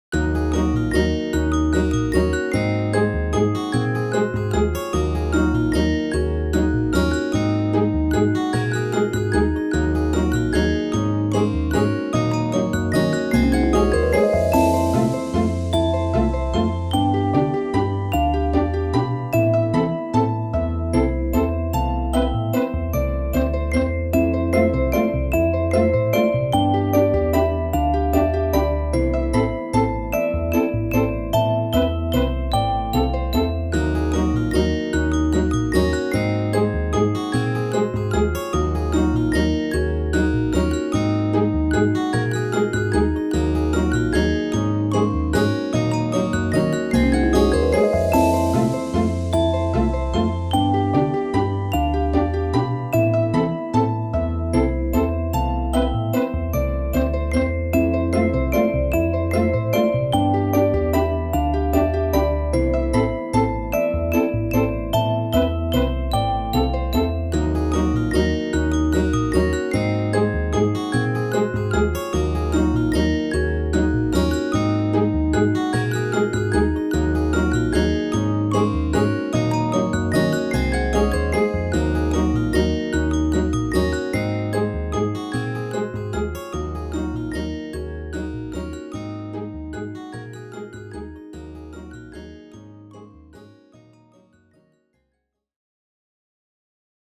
あかるい おしゃれ 異国風 FREE BGM
ファンタジー系オルゴール 楽曲をDownloadする前に、必ず ▶利用規約◀ をご確認ください。